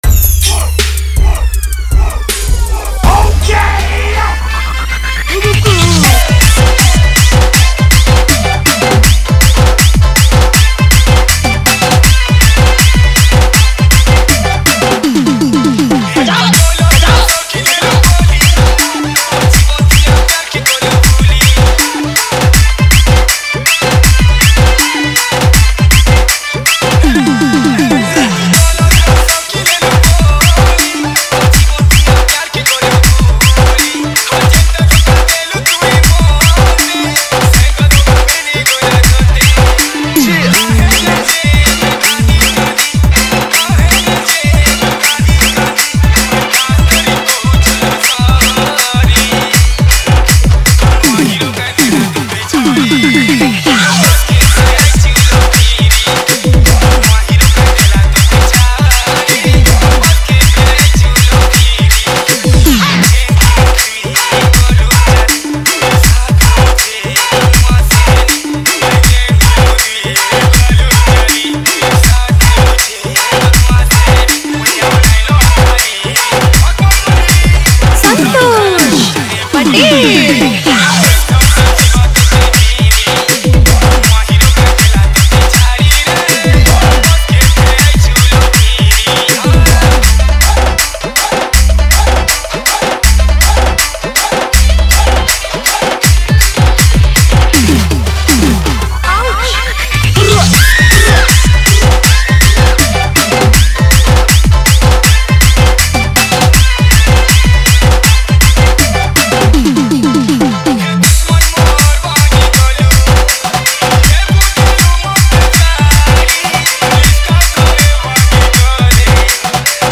Sambalpuri Dj Song 2024
Category:  New Sambalpuri Dj Song 2020